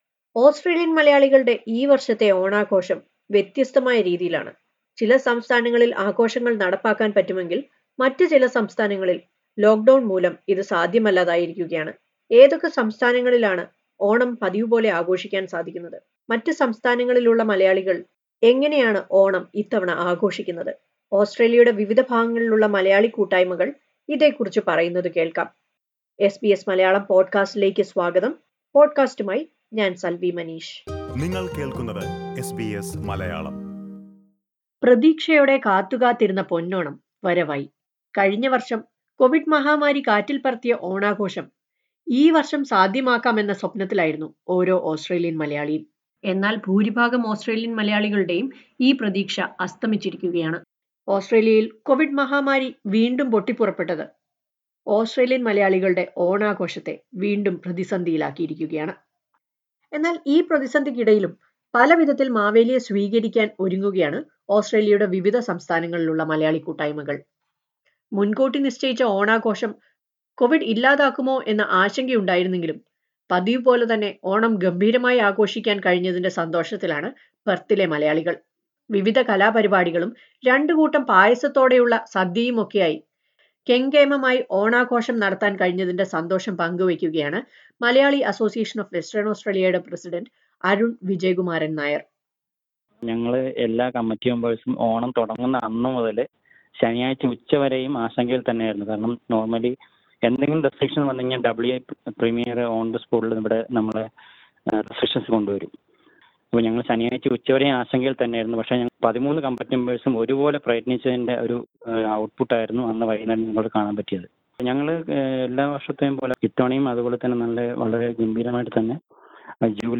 Listen to a report on how Malayalees in different parts of Australia are celebrating Onam amid COVID lockdowns and retrictions.